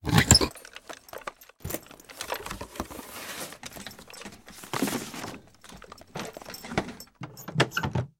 chest_2.ogg